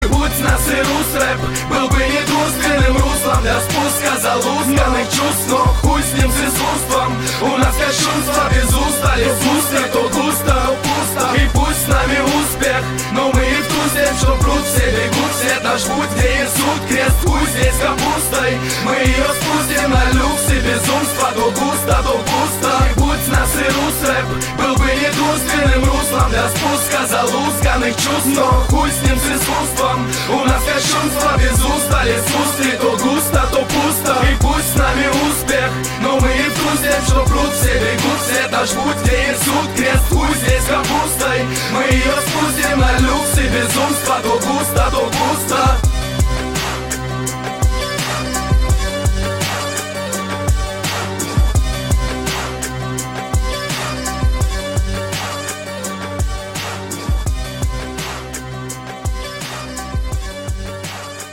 • Качество: 320, Stereo
ритмичные
мужской вокал
Хип-хоп
русский рэп
дуэт